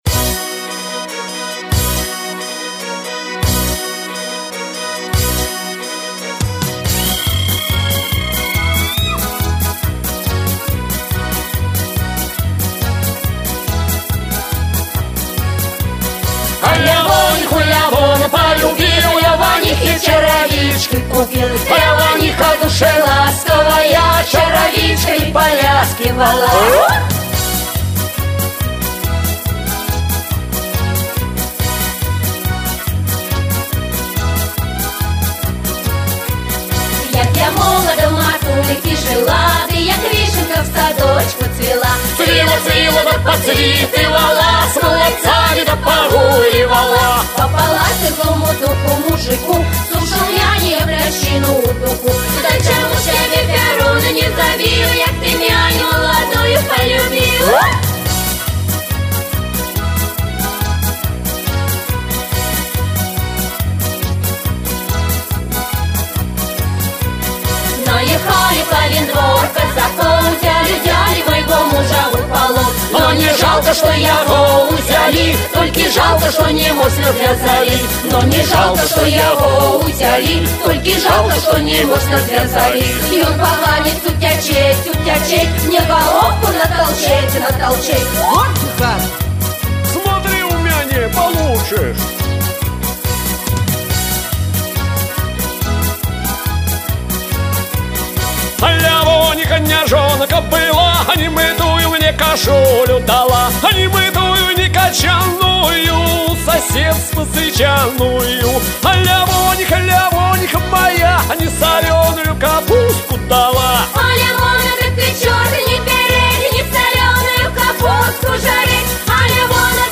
Belorusskiy_narodnyy_tanec-Lyavoniha_Belorussy_Novosibirska.mp3